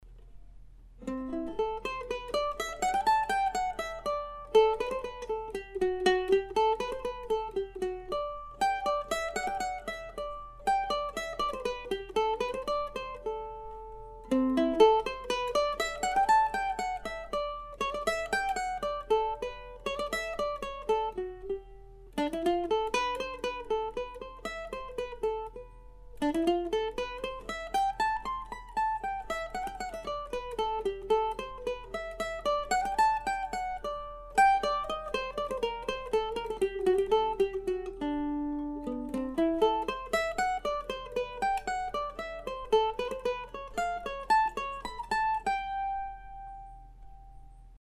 I don't often write for solo mandolin in a "classical" context, although my ongoing series of Deer Tracks pieces are the exception to that rule.